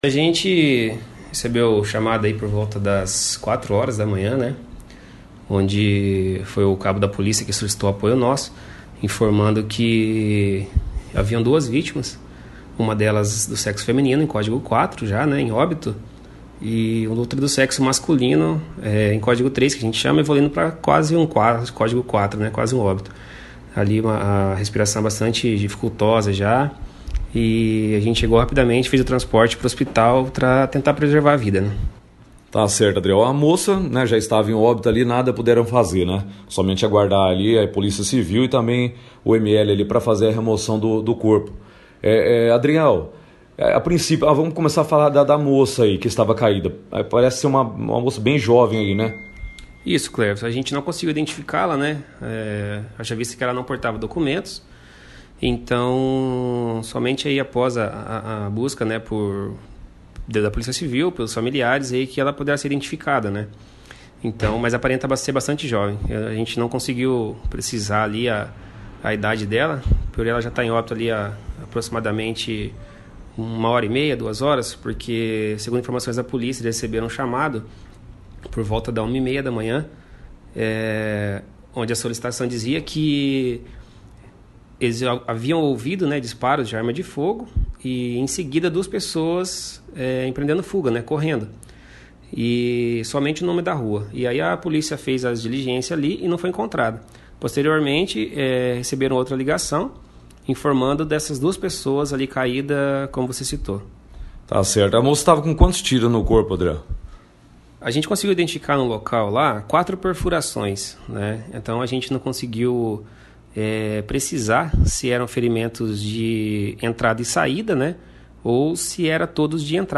participou da 1ª edição do9 jornal Operação Cidade desta terça-feira, 29/09, falando sobre o atendimento.